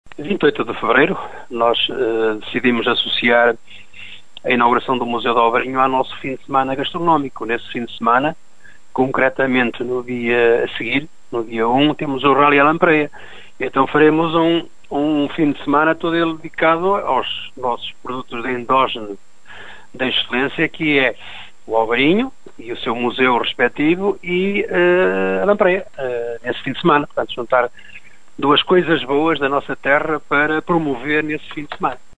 Motivos mais do que suficientes, diz o presidente da Câmara de Monção, Augusto Domingues, para lhe ser dedicado um museu.